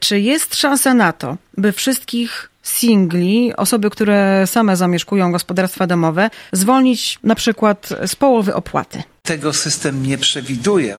Pytaliśmy o to na antenie Radia 5 wiceprezydenta Ełku Artura Urbańskiego.